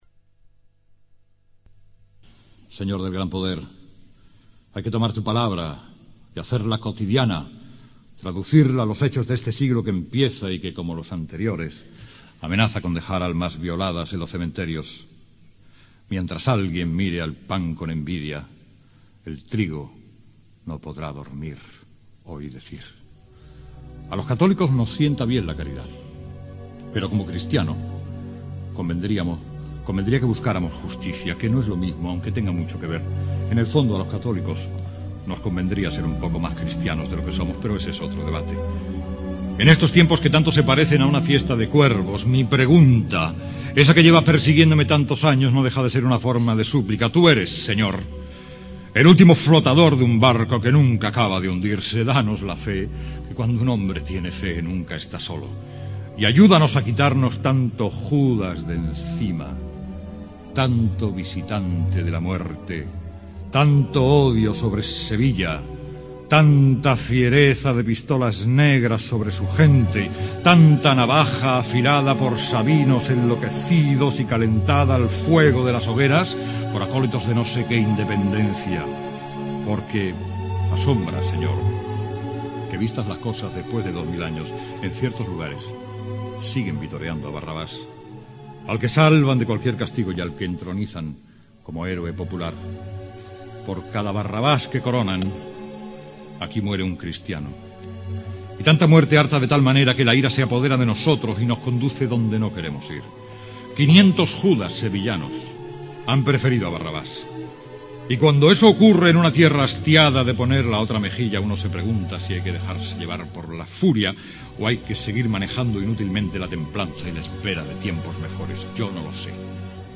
Temática: Cofrade